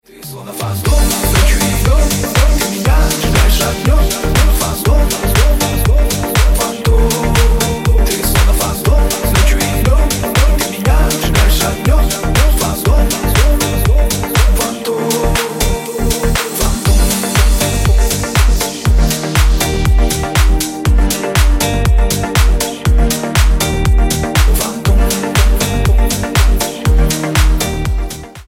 Рингтоны Ремиксы » # Танцевальные Рингтоны